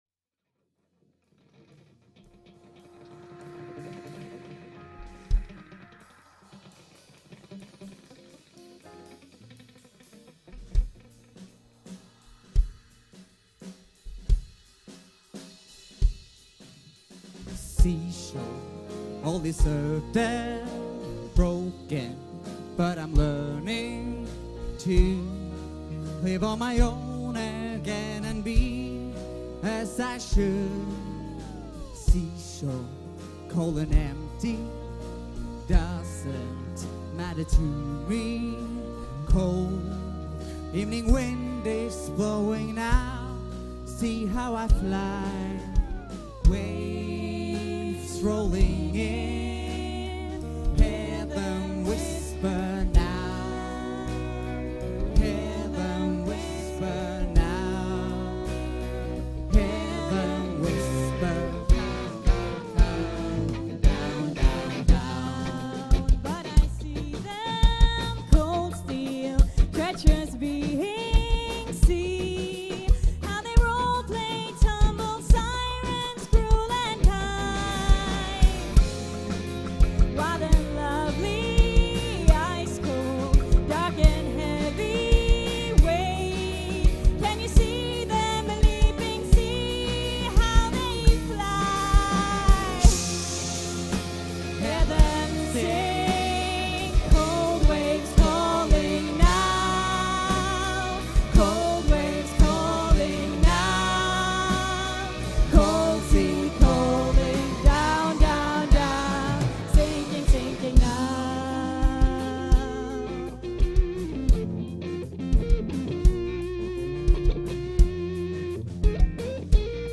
Vocals
Lead Guitar
Rhythm Guitar
Bass
Drums
Laptop